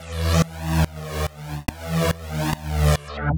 Index of /musicradar/uk-garage-samples/142bpm Lines n Loops/Synths
GA_SacherPad142E-03.wav